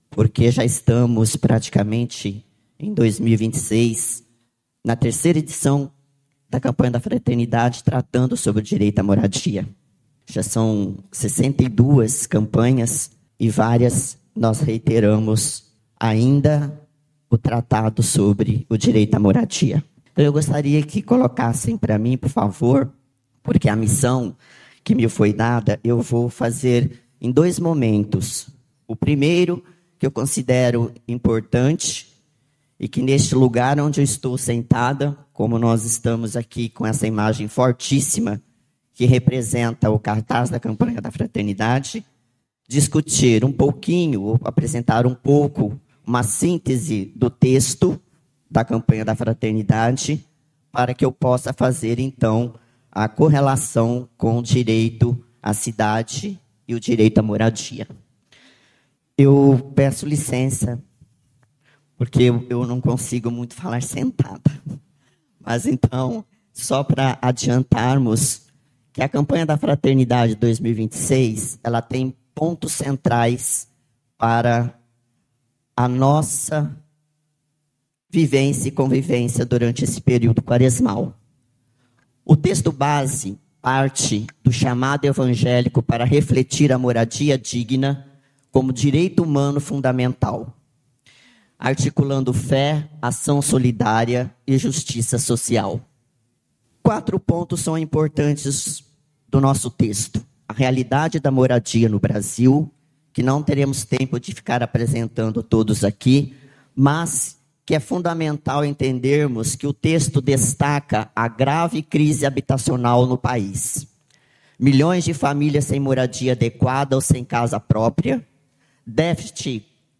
O evento foi transmitido pela Rádio Caiari, alcançando toda a região de Porto Velho pela frequência 103,1 FM, além da região de Ariquemes e todo o Vale do Jamari pela 95,3 FM.